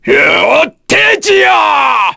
Phillop-Vox_Skill3_kr.wav